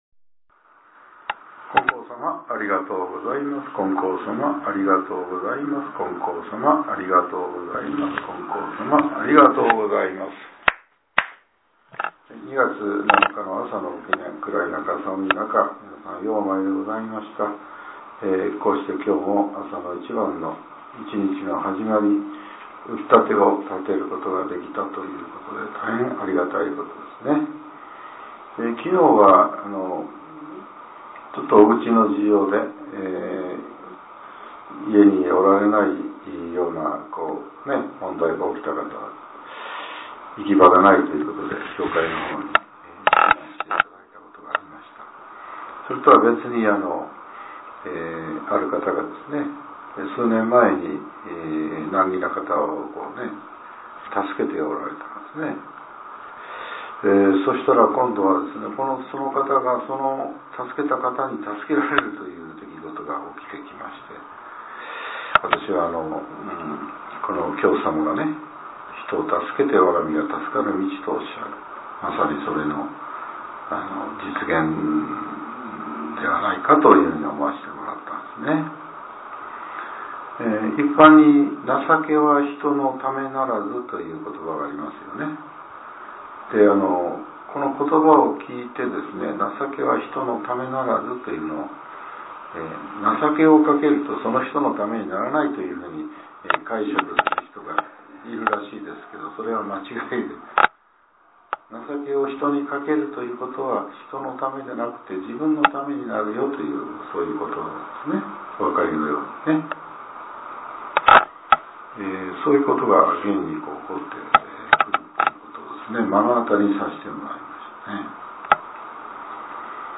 令和８年２月７日（朝）のお話が、音声ブログとして更新させれています。